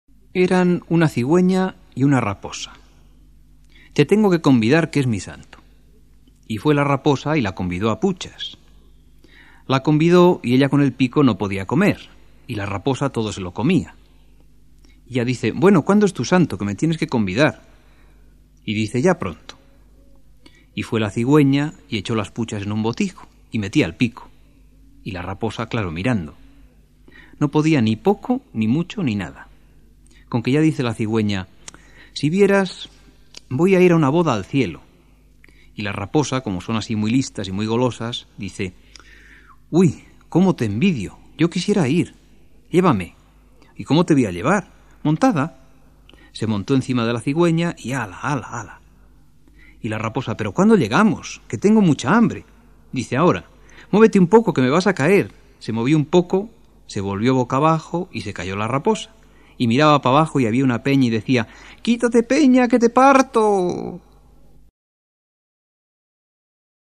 100 temas infantiles
Intérpretes: Voz: Joaquín Díaz